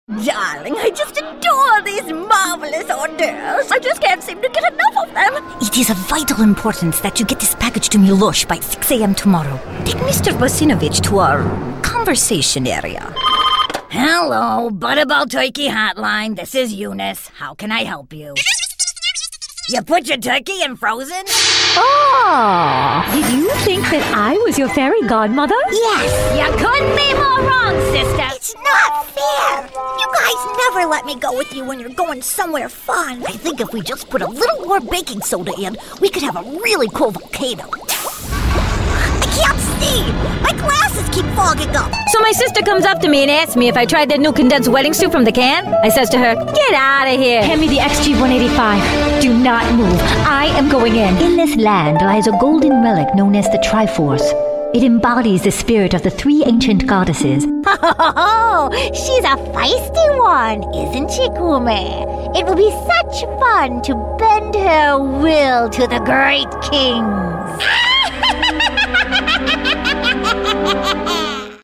Clients love her genuine sound and accomplished acting abilities.
Sprechprobe: Sonstiges (Muttersprache):